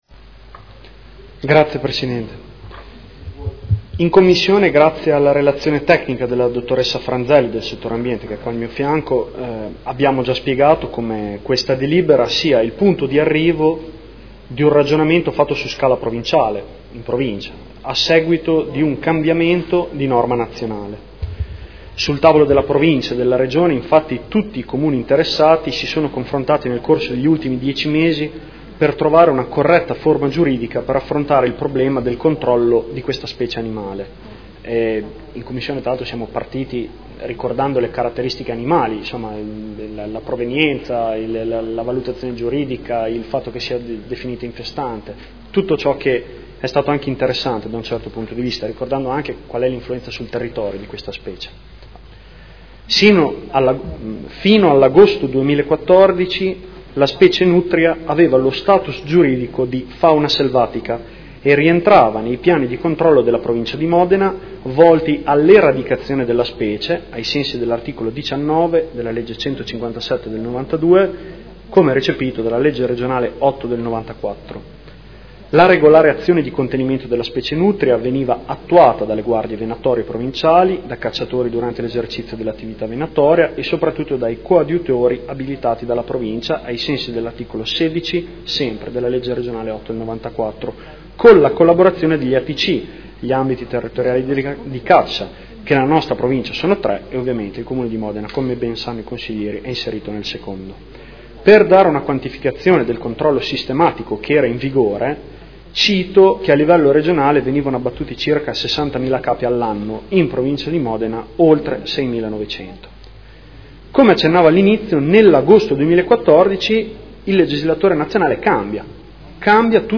Seduta del 09/07/2015 Approvazione convenzione per il controllo della specie nutria